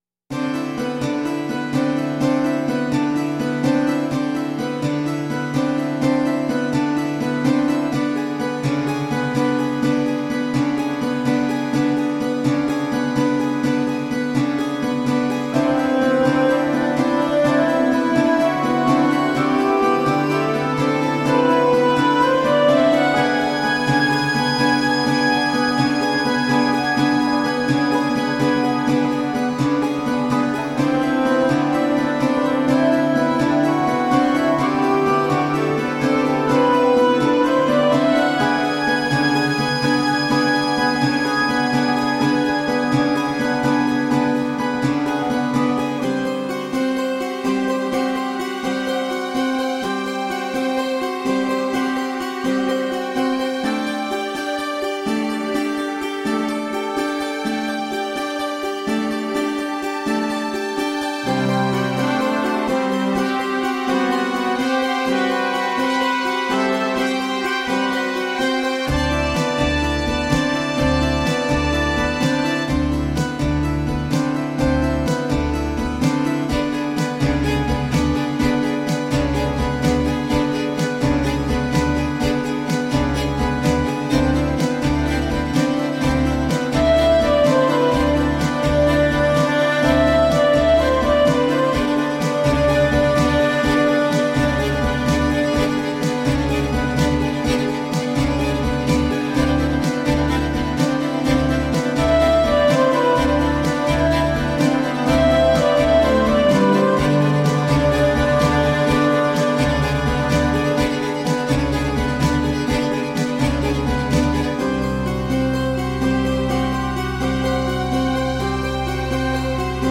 Tagged as: New Age, Other, Viola da Gamba